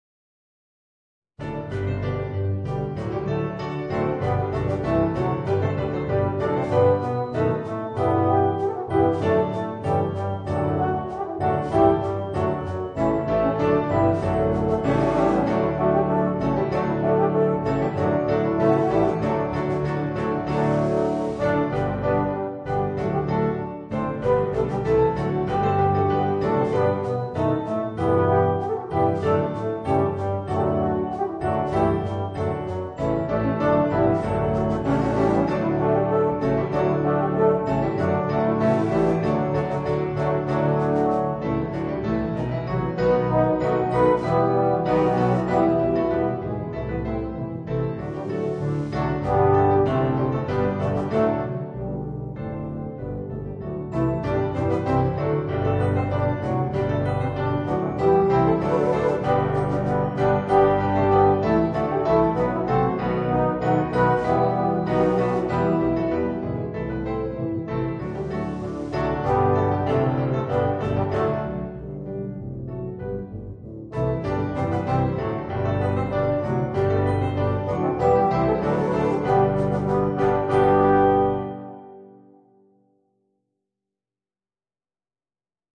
Voicing: 2 Baritones, 2 Euphoniums, 4 Tubas